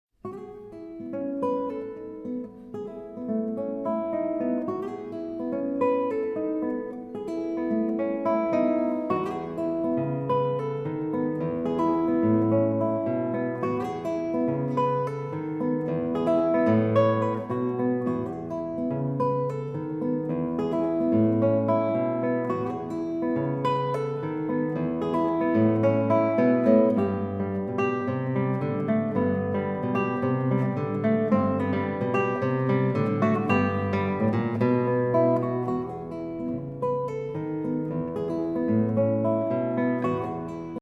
Gitarre (Guitar)